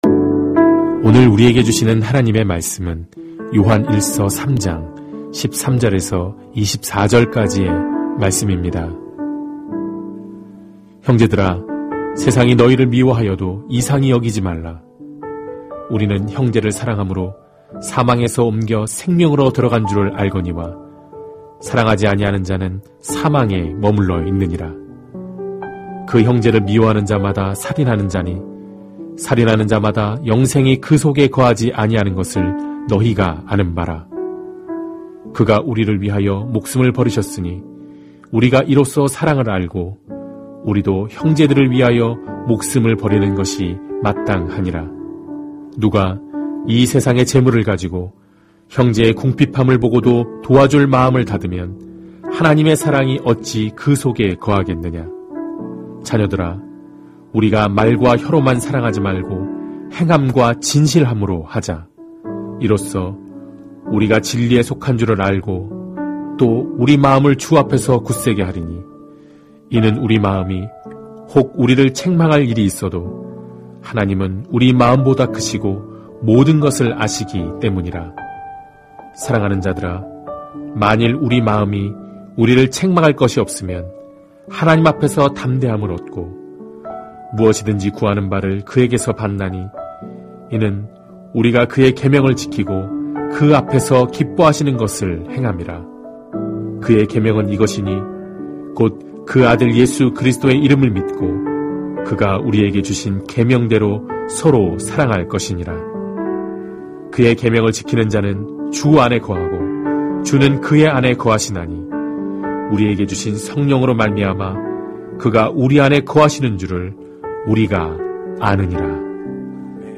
주일예배